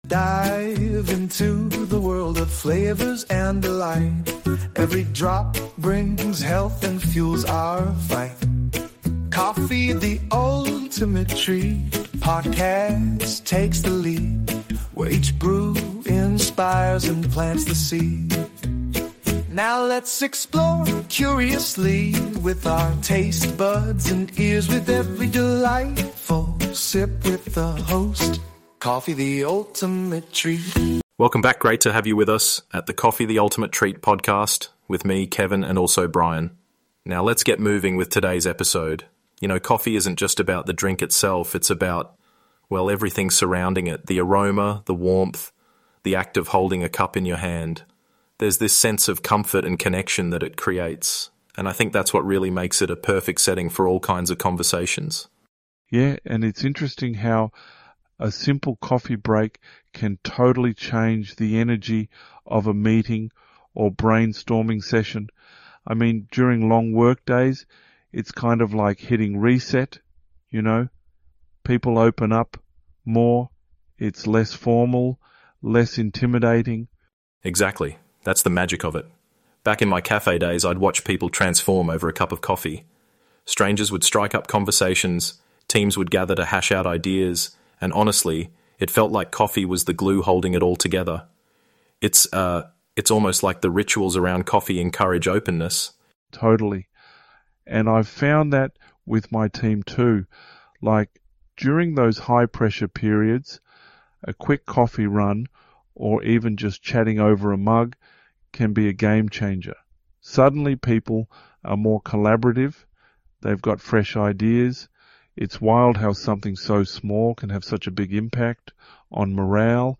Join us for a conversation that’s bound to warm your heart and spark your creativity.